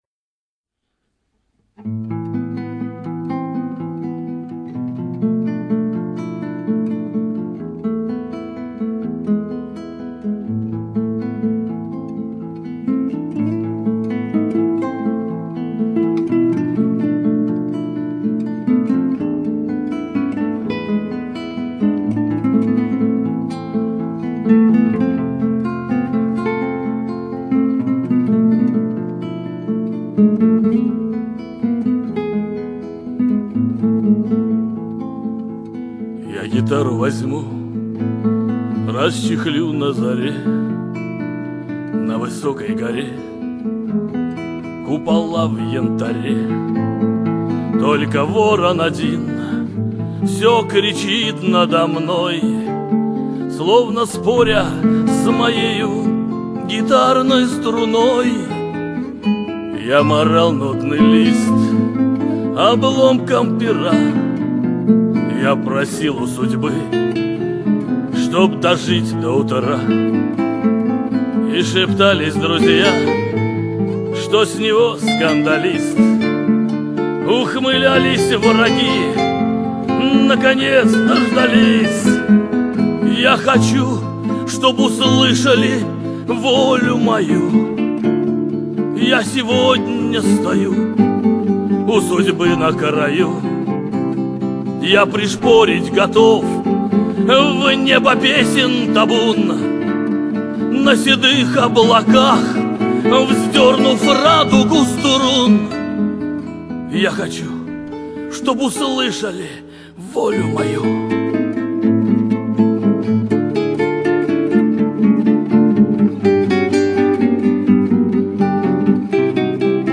Небольшая подборка прекрасного шансонье...